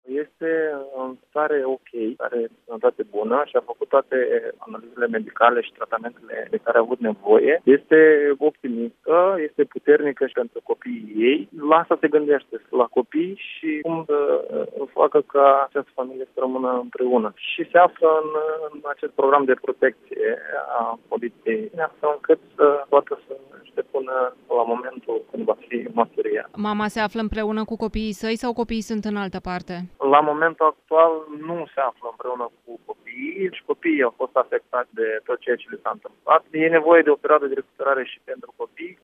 Secretarul de stat în Ministerul pentru Românii de Pretutindeni  Veaceslav Şaramet, care conduce o delegaţie comună a MRP – MAI în regiunea Lamezia Terme, a declarat sâmbătă, pentru Europa FM, că în cazul agresorului româncei sechestrate în Italia s-a trecut de la restul preventiv la arestul efectiv şi că femeia se află în prezent într-un program de protecţie a martorilor.